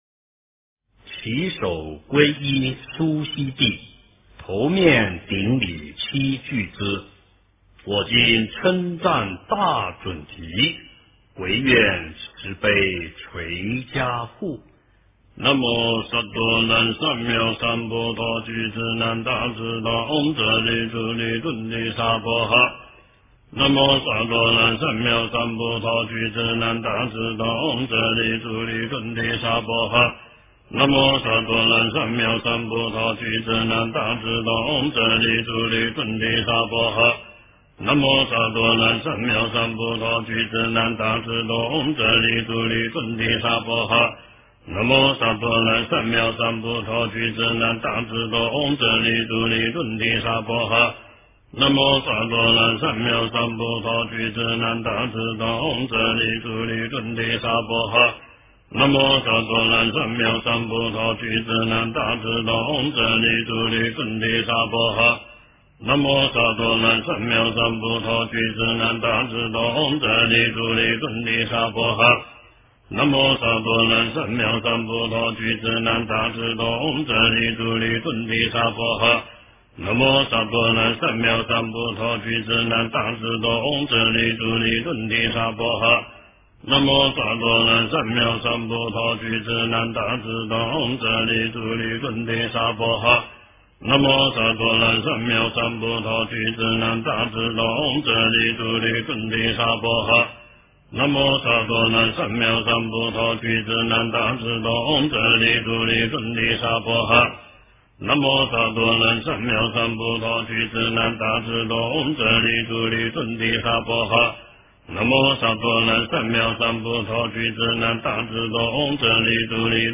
佛音 经忏 佛教音乐 返回列表 上一篇： 文殊菩萨盛名咒-唱颂版--未知 下一篇： 大悲咒-功课--寺院 相关文章 晚课-大悲咒--永平寺 晚课-大悲咒--永平寺...